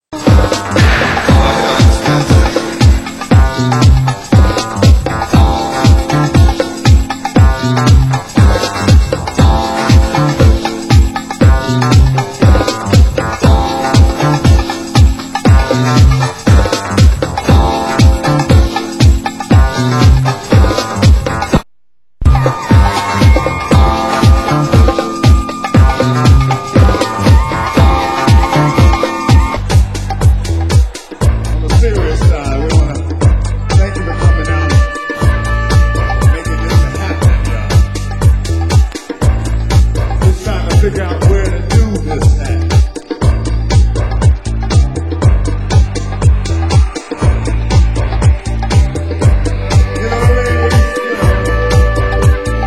Genre: Funky House